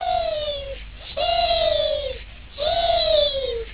• "Heave, Heave, Heave..." to be chanted in the manner of